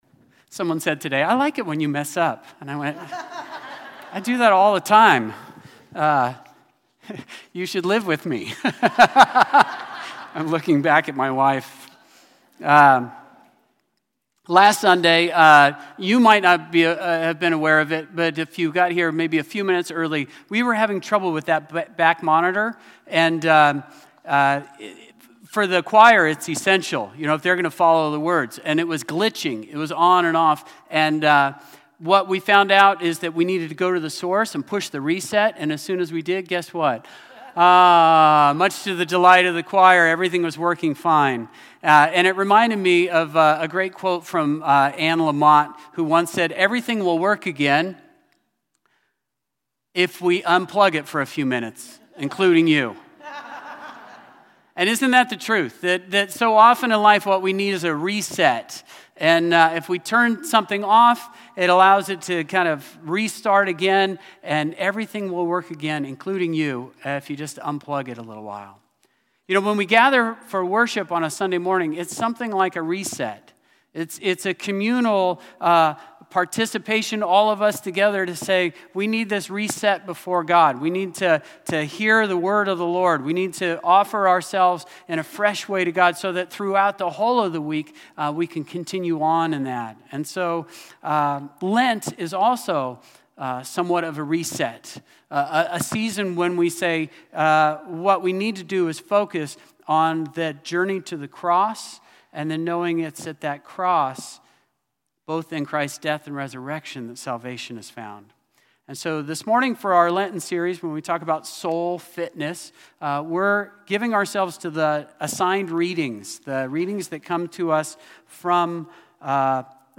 Play Rate Listened List Bookmark Get this podcast via API From The Podcast You are listening to the St. Peter's By-the-Sea Presbyterian Church sermon podcast.